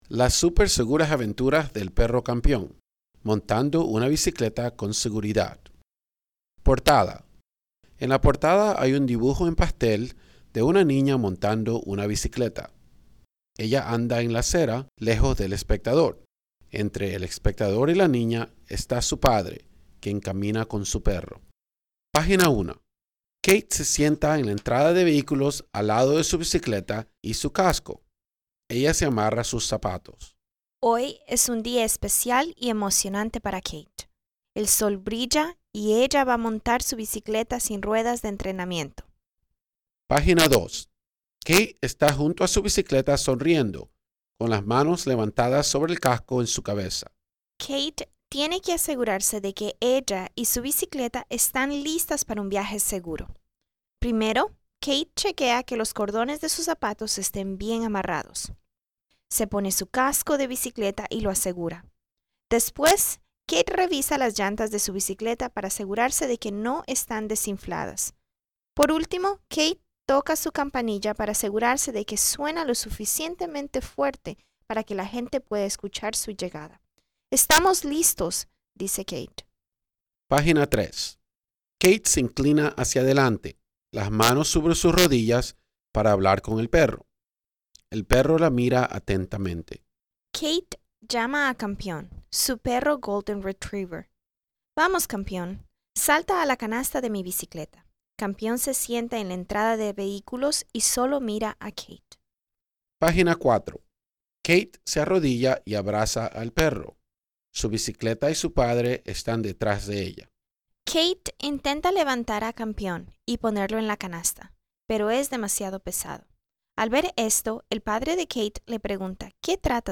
spanish_childrens_book_project_audio.mp3